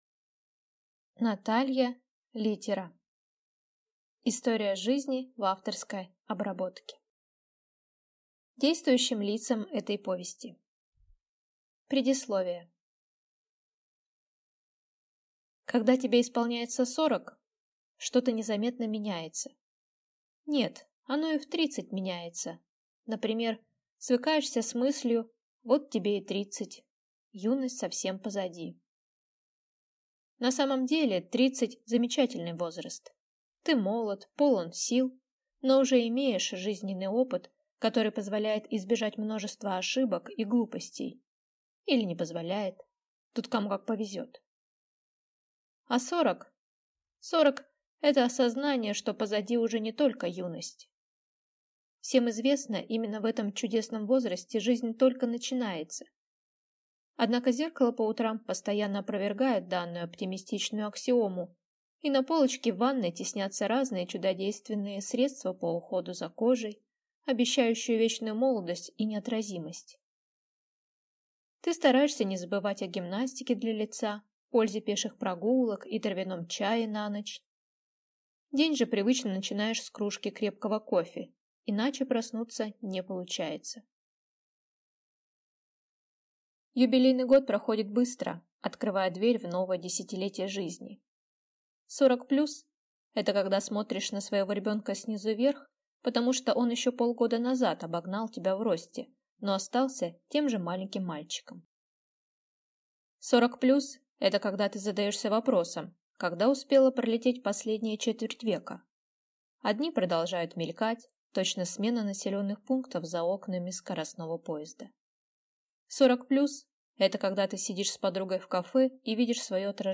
Аудиокнига История жизни в авторской обработке | Библиотека аудиокниг